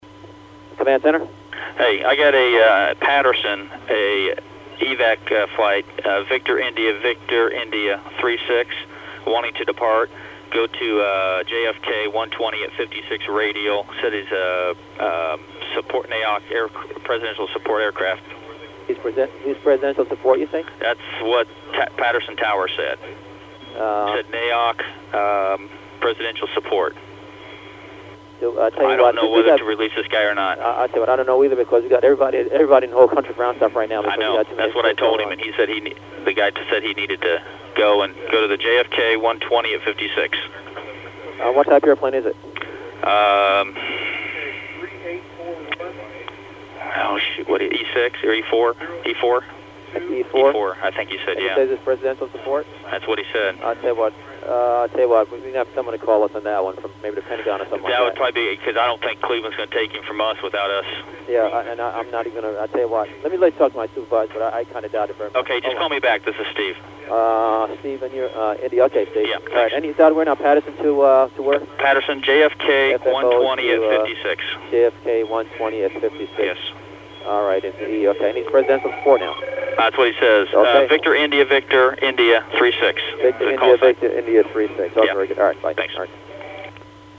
The caller was Indianapolis Center, ZID.
The caller continually refers to the flight as “Presidential support.”